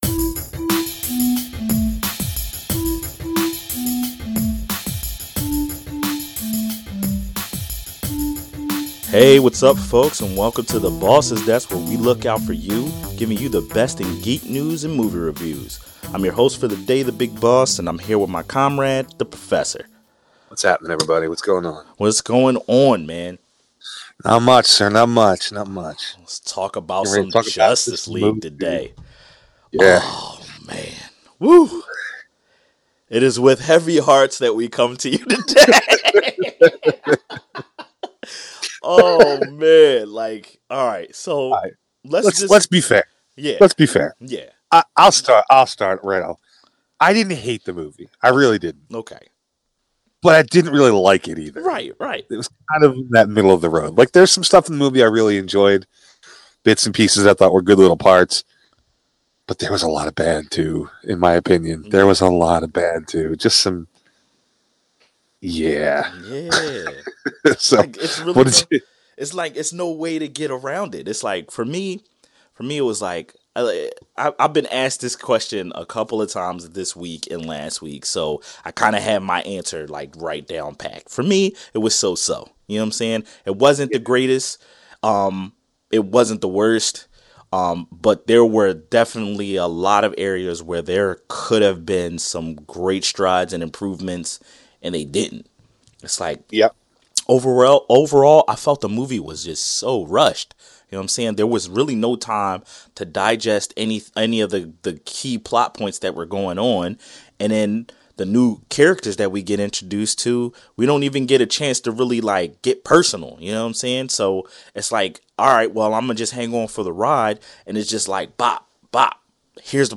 Movie Review - Justice League